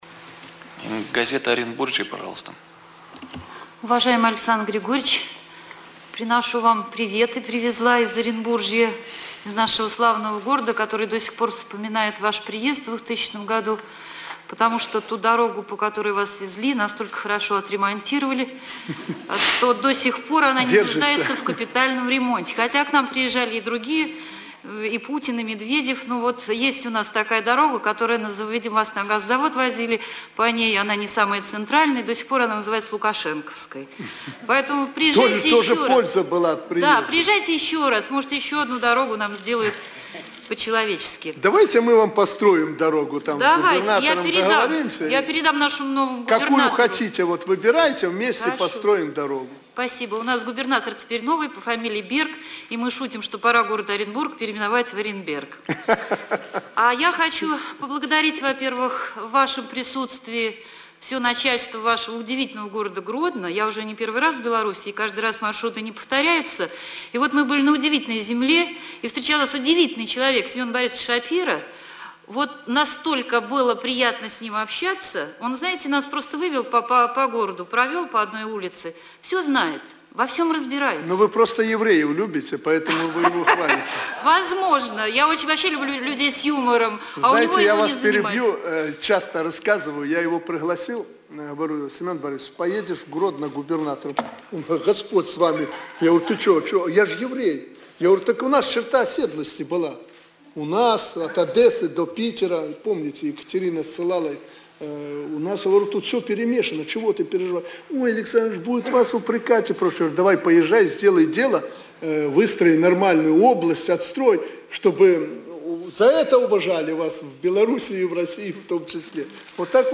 Прэсавая канфэрэнцыя Аляксандра Лукашэнкі для расейскіх журналістаў. Менск, 11 кастрычніка 2013